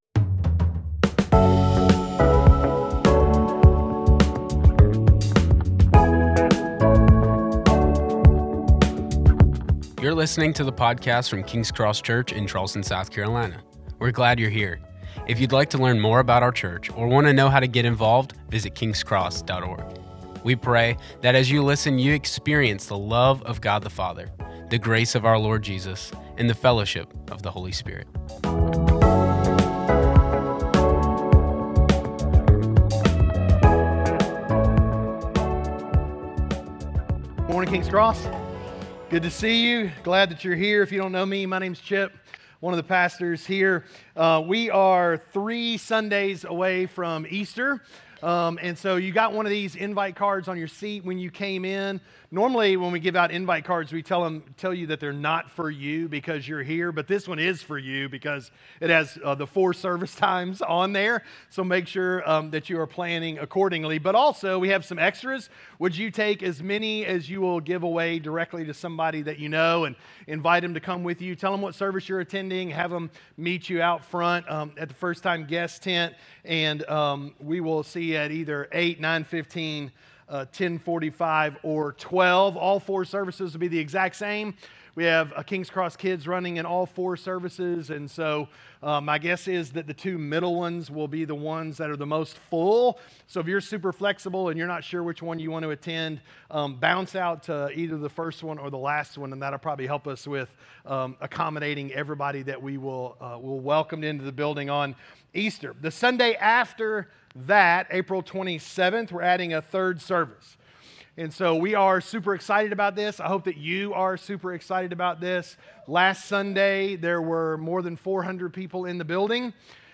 A message from the series "Philippians." Keeping the Gospel is the main thing.